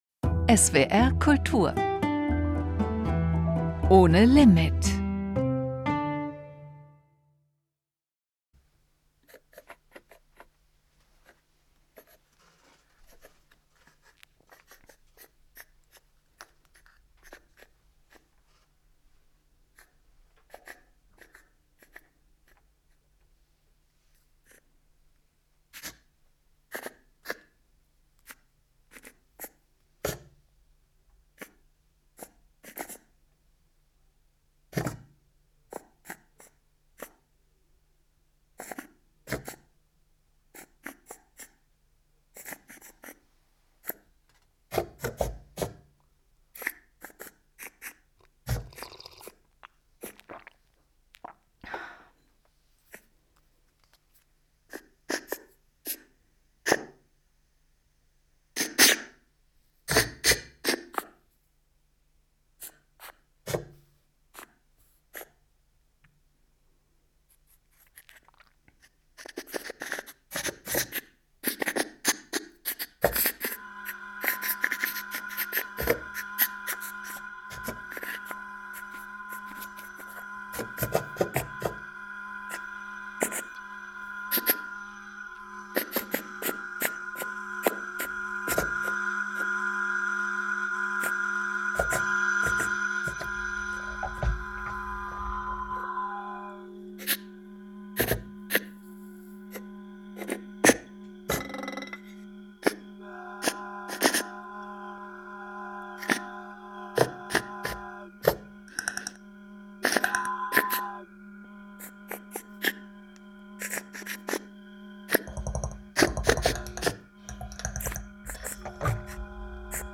Konzertmitschnitt vom 17.